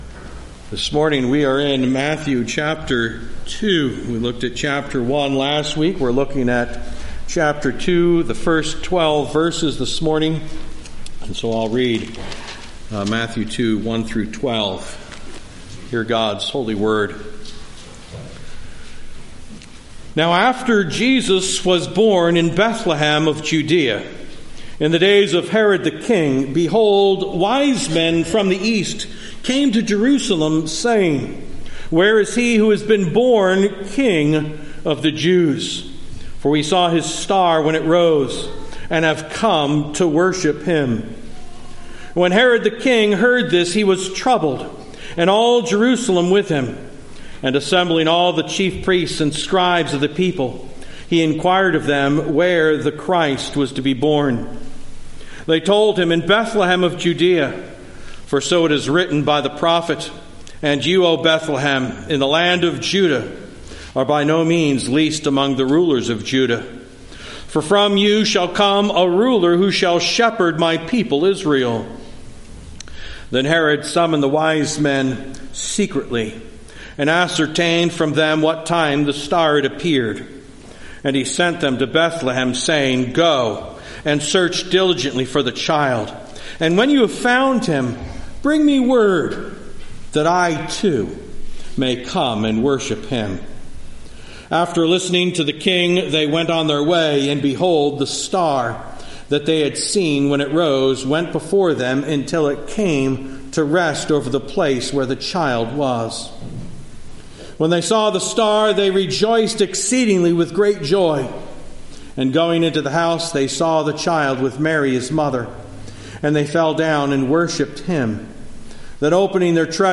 Morning Sermon